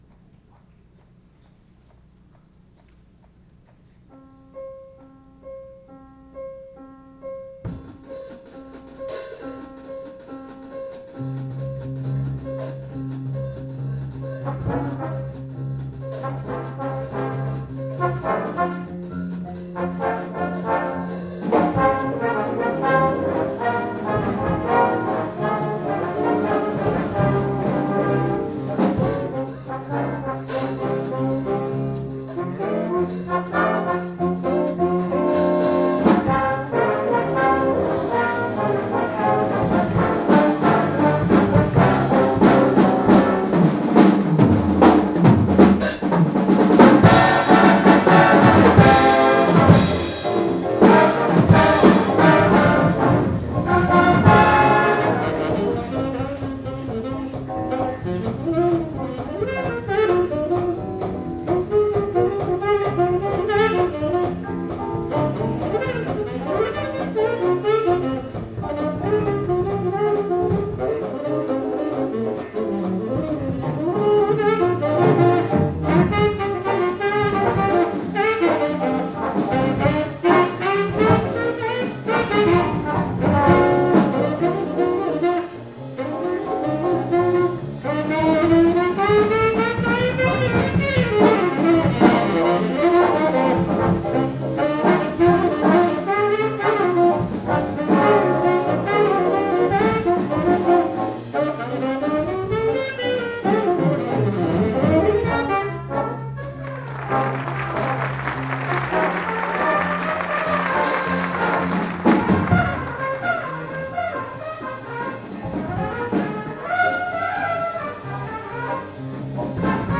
fzs_jazz_98_mmea_the_heats_on.ogg